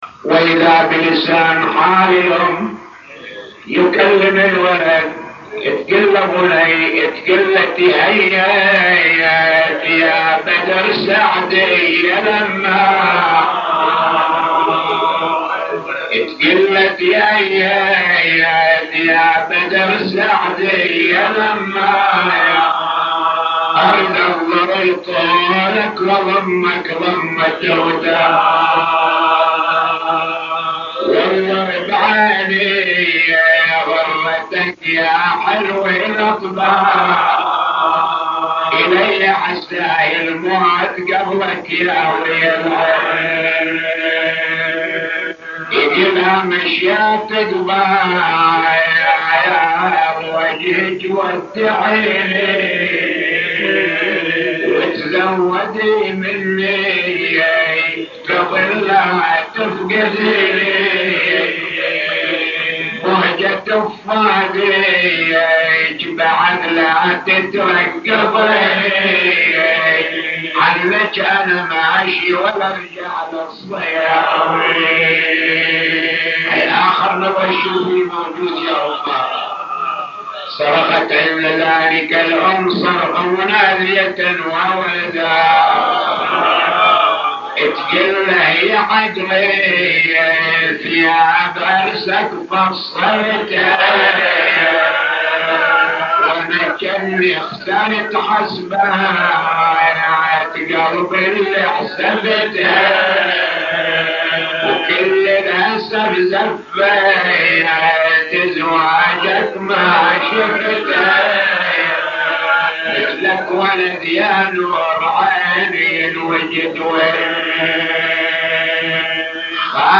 نواعي وأبيات حسينية – 15